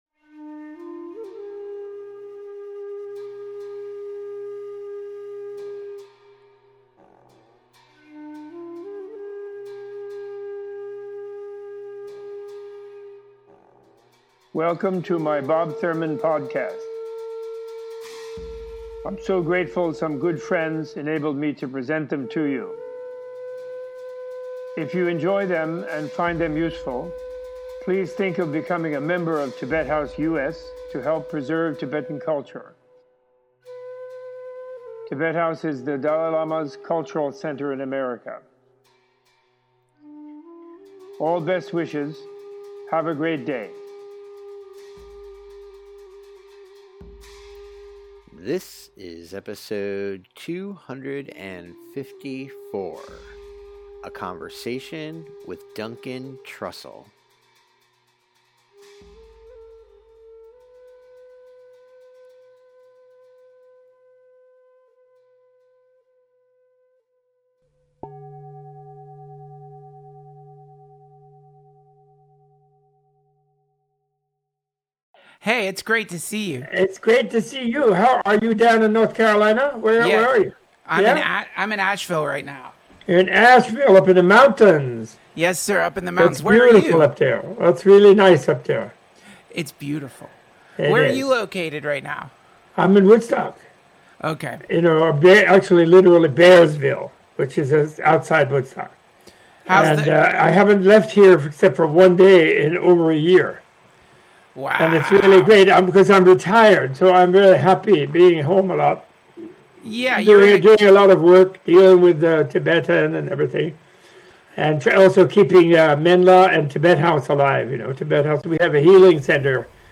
Tibet-House-US-Menla-Conversation-with-Duncan-Trssell-Bob-Thurman-Podcast-Ep-254.mp3